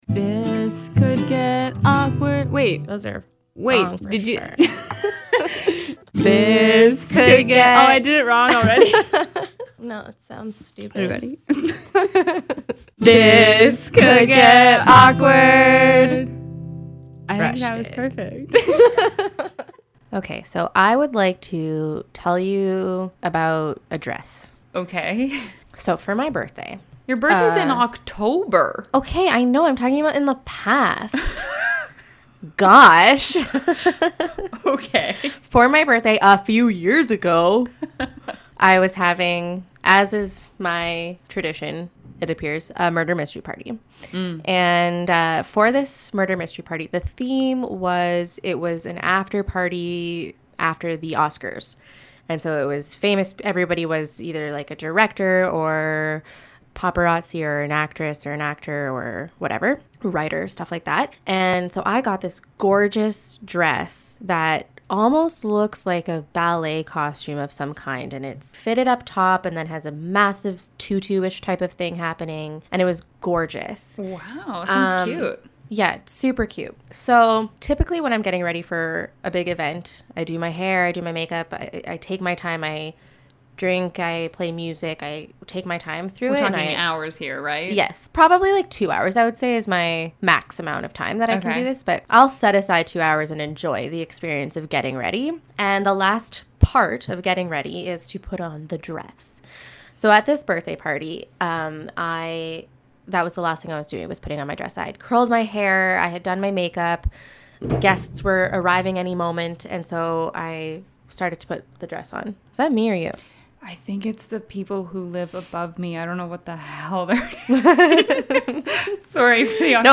This Could Get Awkward » Page 21 of 65 » A podcast by two awesome women.